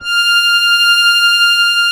MUSETTESW.20.wav